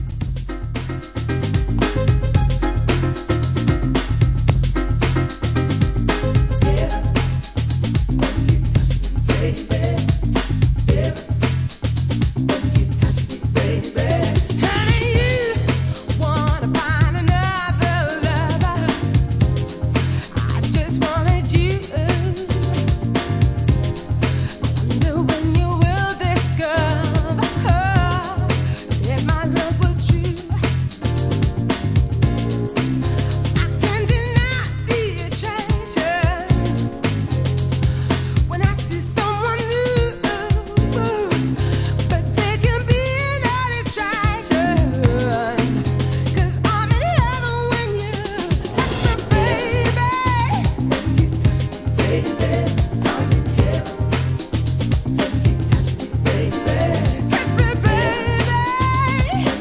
HEAVY CLUB MIX